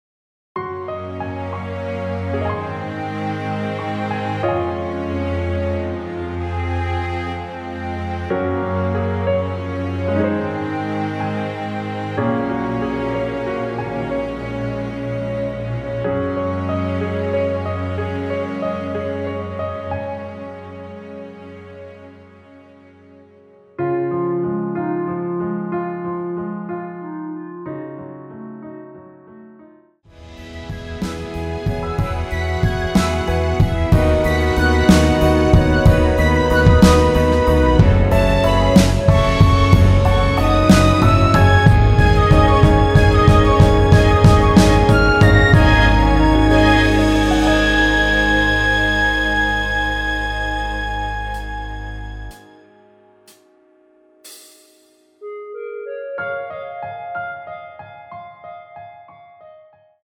3분 14초경 무반주 후 노래 들어가는 부분 박자 맞추기 쉽게 카운트 추가하여 놓았습니다.(미리듣기 확인)
원키 멜로디 포함된 MR입니다.
Db
앞부분30초, 뒷부분30초씩 편집해서 올려 드리고 있습니다.
중간에 음이 끈어지고 다시 나오는 이유는